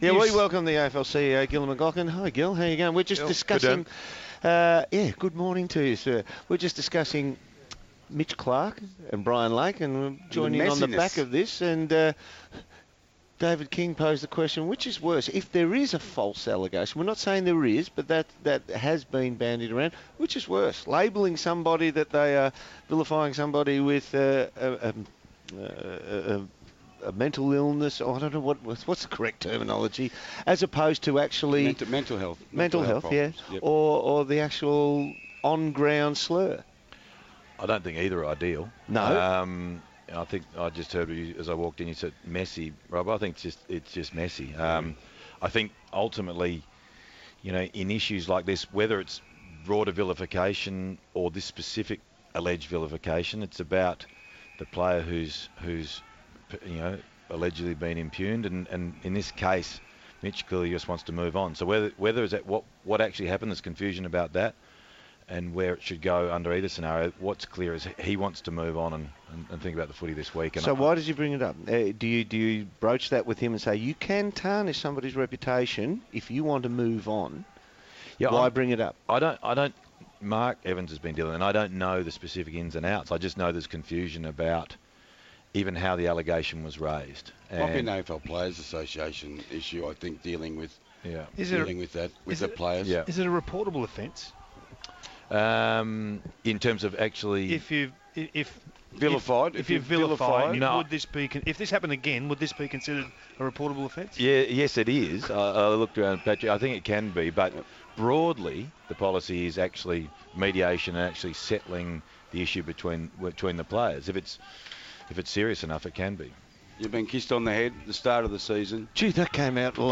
AFL CEO Gillon McLachlan joined the boys on Crunch Time to discuss all the hot topics in the AFL.